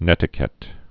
(nĕtĭ-kĕt, -kĭt)